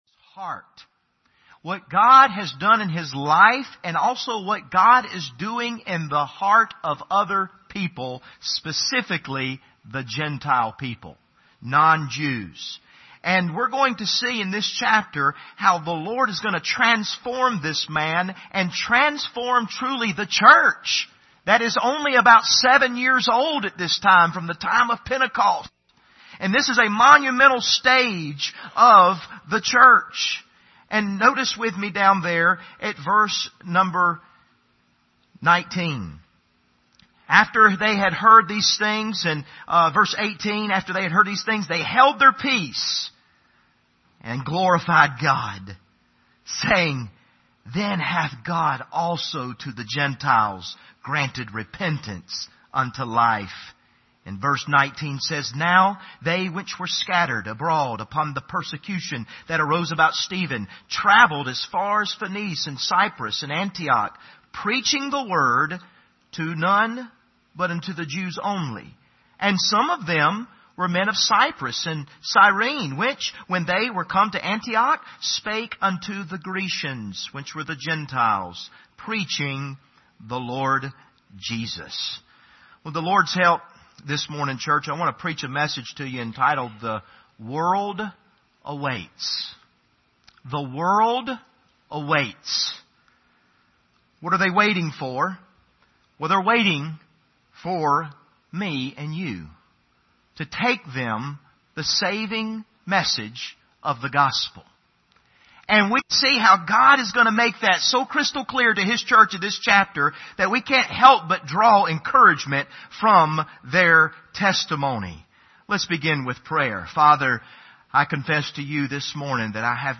Bible Text: Acts 11:1-4, 18-20 | Preacher
Service Type: Sunday Morning